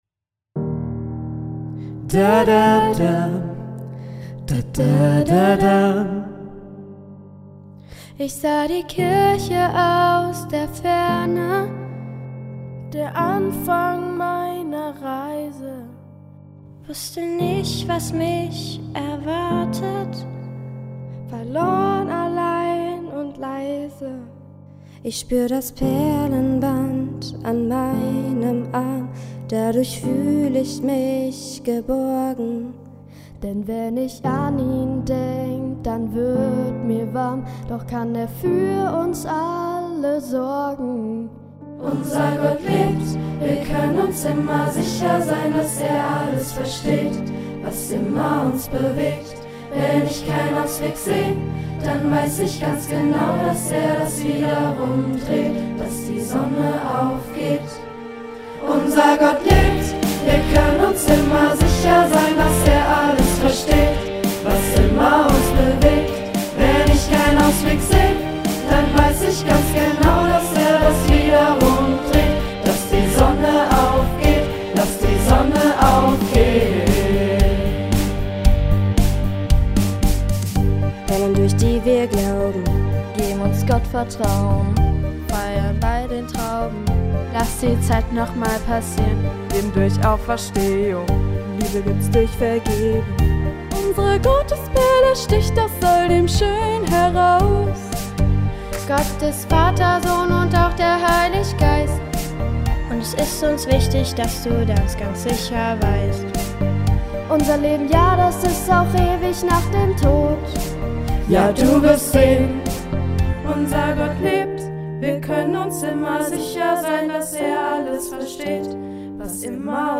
Sobald Melodie und Text stehen, wird das Ergebnis eingesungen, geschnitten und auf dem Vorstellungsgottesdienst präsentiert.